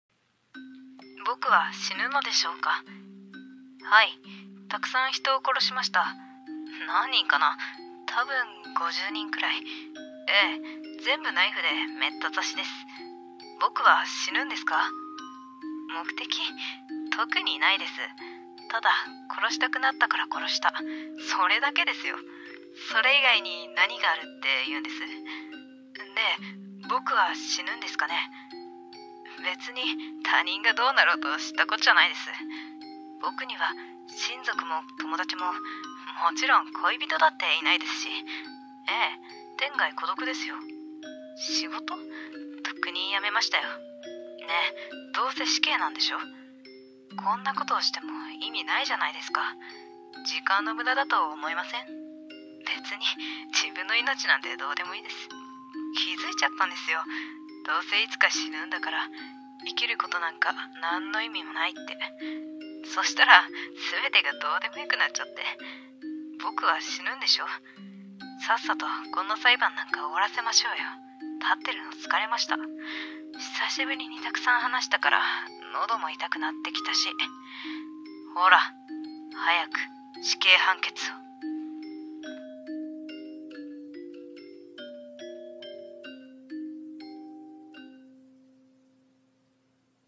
声劇「死刑裁判」